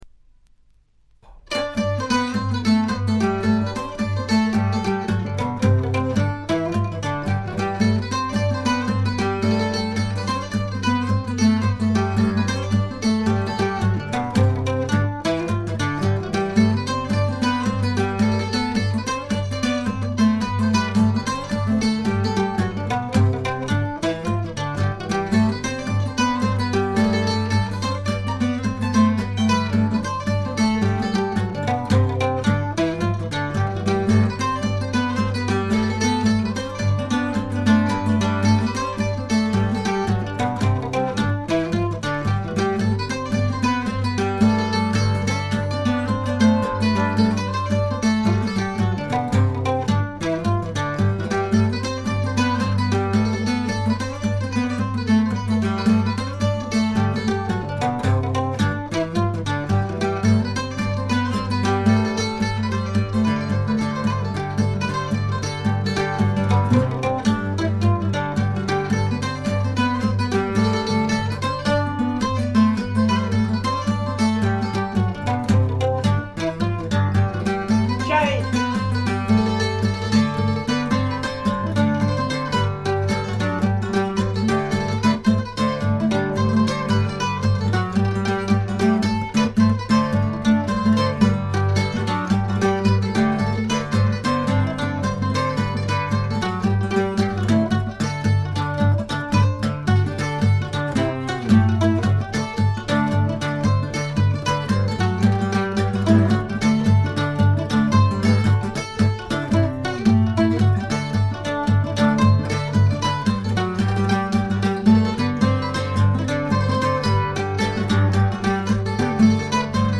ところどころでバックグラウンドノイズ、チリプチ。散発的なプツ音少々。
試聴曲は現品からの取り込み音源です。
Guitar
Mandolin